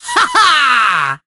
penny_kill_vo_03.ogg